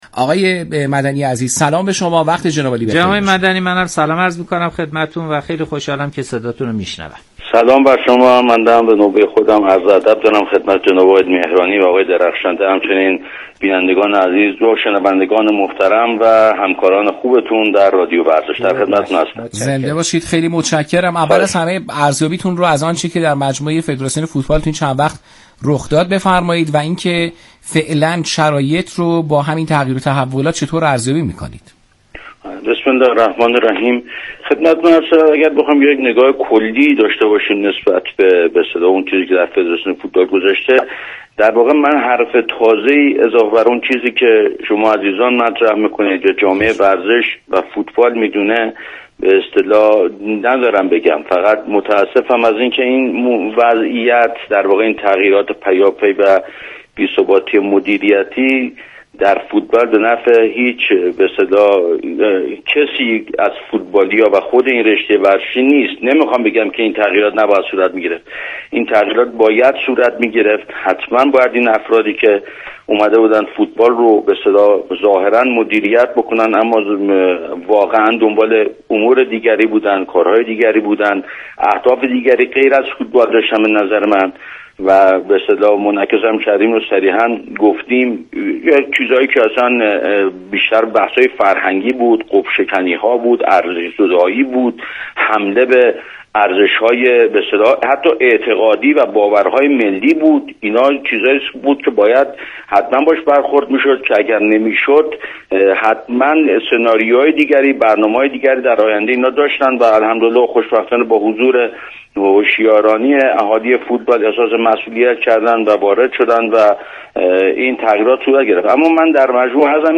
کیهان ورزشی- برای شنیدن گفت وگو اینجا را کلیک کنید برچسب ها: فدراسیون فوتبال ، مدیریت در ورزش ، مدیریت در فوتبال ، فساد در فوتبال ایران ، شهاب الدین عزیزی خادم X Share Stumble Upon Delicious Cloob Digg نظر شما نام ایمیل * نظر